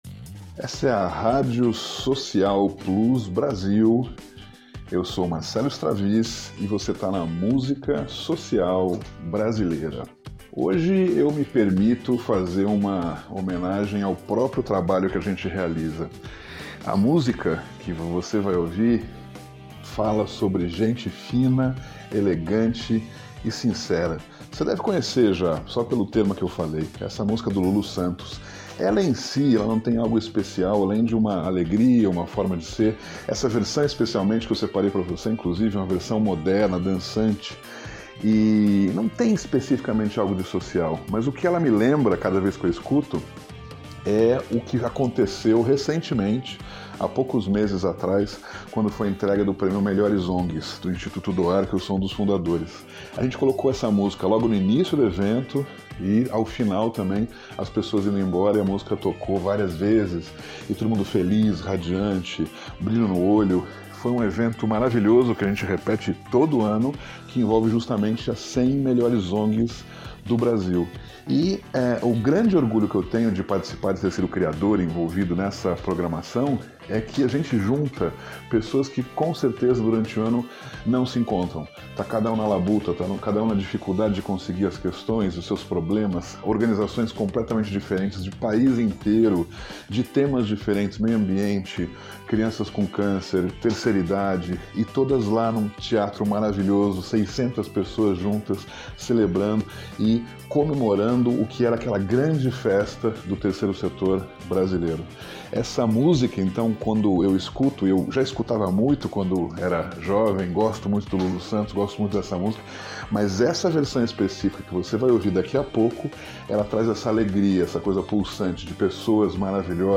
com um viés moderno, animado e revigorante